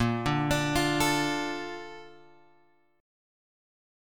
A# Major 7th